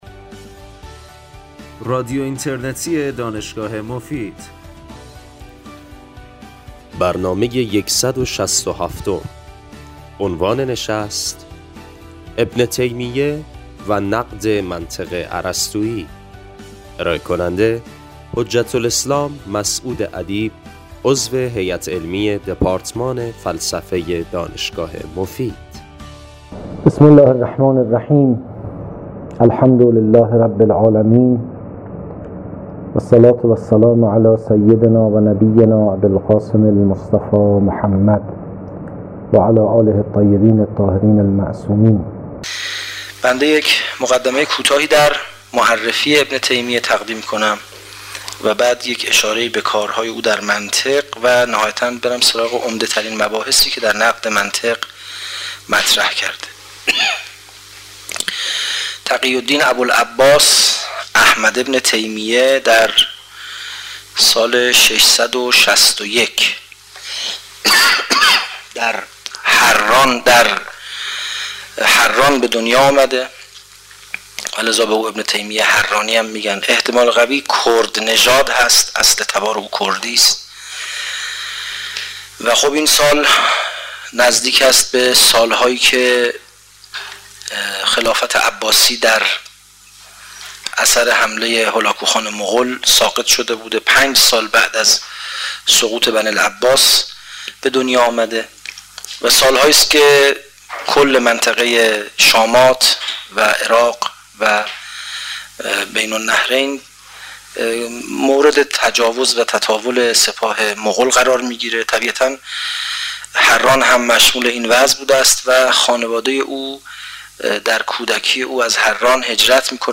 بخش پایانی برنامه به پرسش و پاسخ اختصاص دارد.